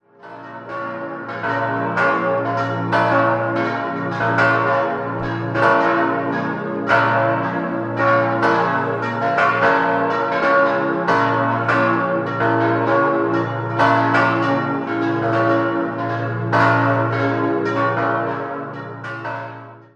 Die barocke Wallfahrtskirche wurde 1662 bis 1676 errichtet, wobei die Doppelturmfassade erst 1692 ergänzt wurde. Sehenswert im Inneren sind die Kassettendecke und Kunstwerke von Franz und Jakob Bertle. 5-stimmiges erweitertes B-Moll-Geläute: b°-des'-f'-as'-b' Alle Glocken wurden 1949 von der Gießerei Oberascher (Salzburg) in Sonderlegierung hergestellt.